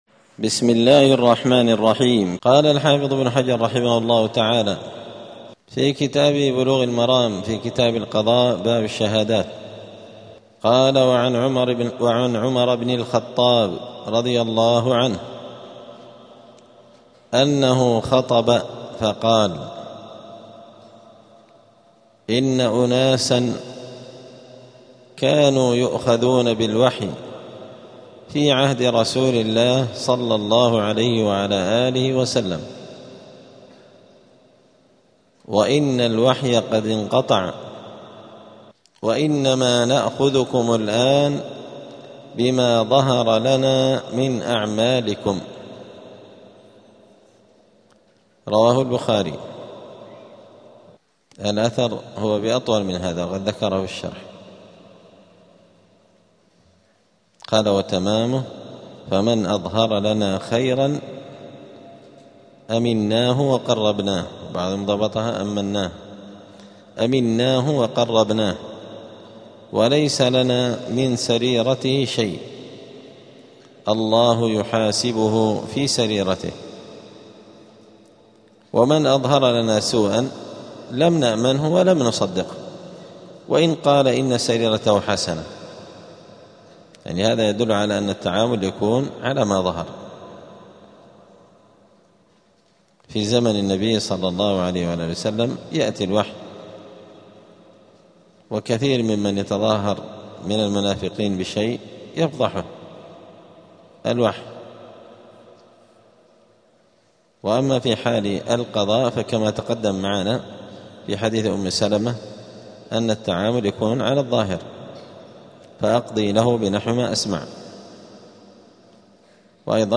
*الدرس الثامن عشر (18) {ﻗﺒﻮﻝ ﺷﻬﺎﺩﺓ ﻣﻦ ﻟﻢ ﻳﻈﻬﺮ ﻣﻨﻪ ﺭﻳﺒﺔ}*
دار الحديث السلفية بمسجد الفرقان قشن المهرة اليمن